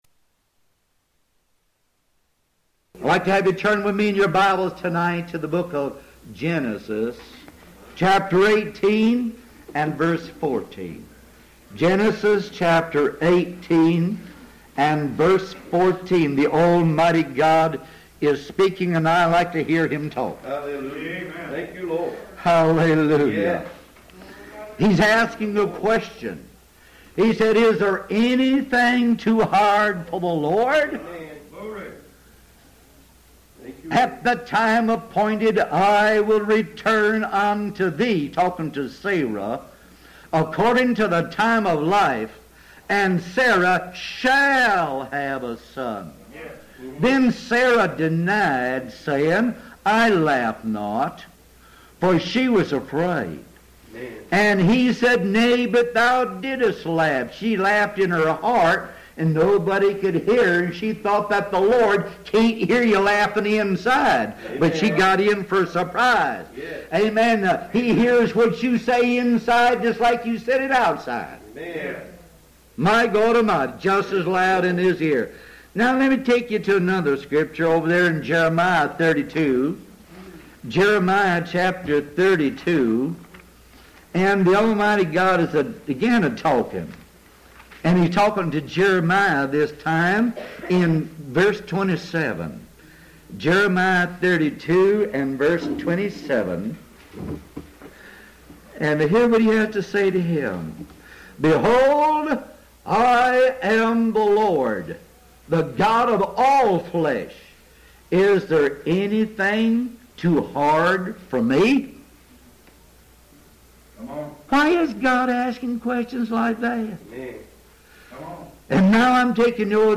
Bible Lessons near Akron, OH complete with 268 sermons for your listening pleasure.